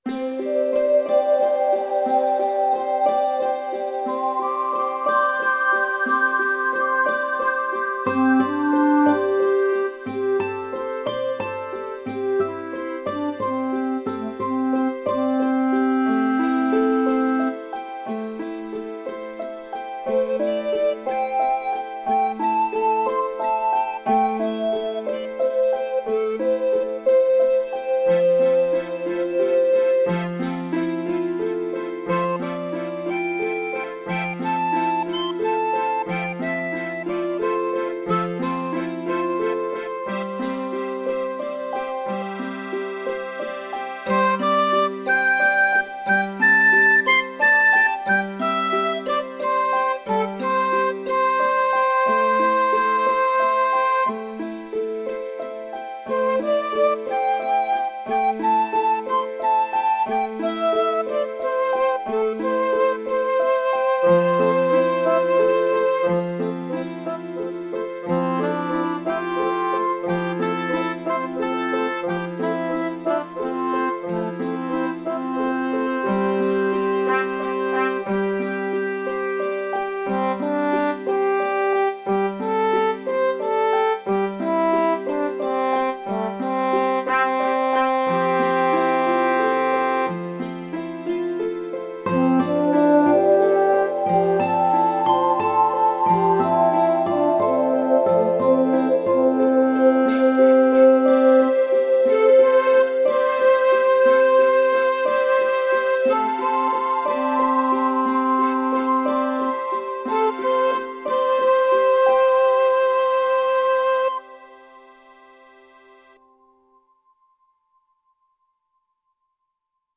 The notes used are low A, C, D, E, G, A, and high C.